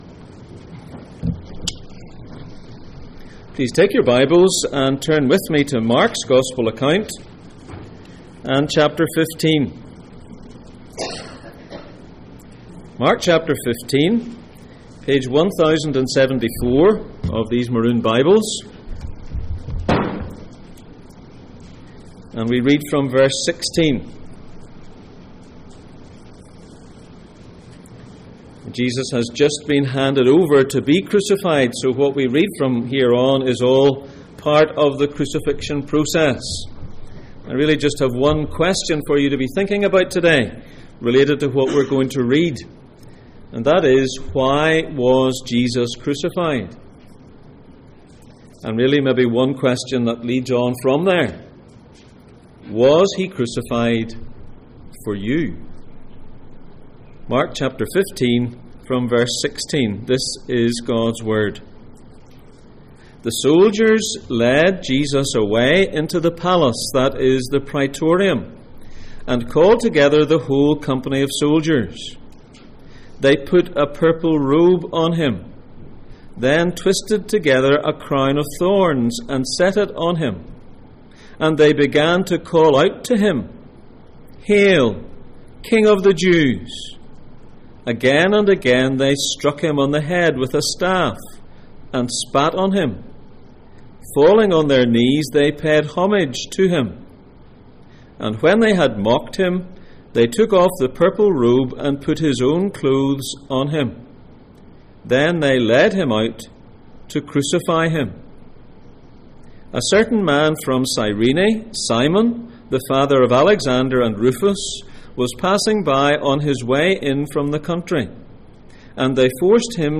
Mark 8:34 Service Type: Sunday Morning %todo_render% « Jesus’ death